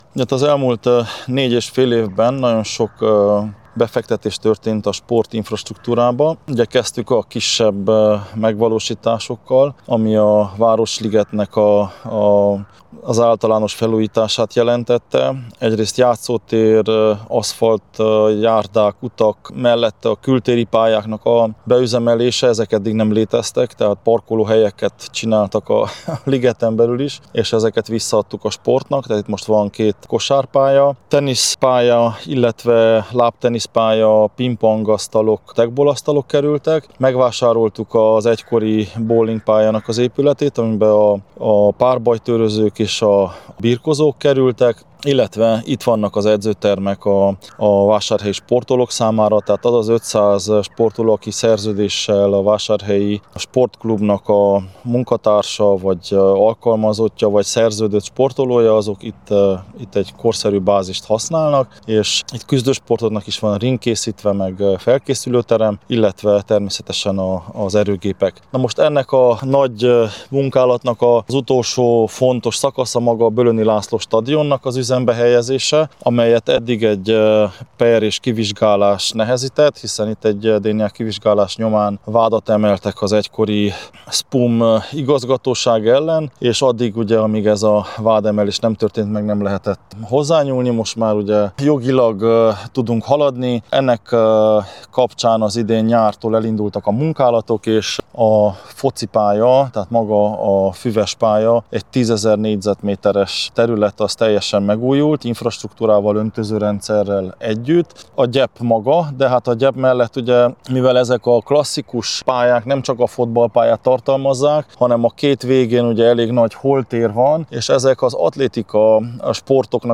Soós Zoltán polgármesterrel a helyszínen beszélgetett a sportberuházásokról